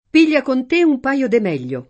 meglio [m$l’l’o] avv.